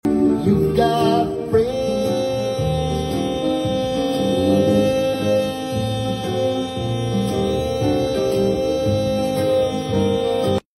🔈Does Your Planer Sing Along?? sound effects free download